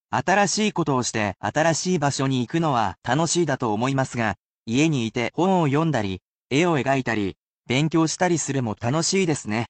[basic polite speech]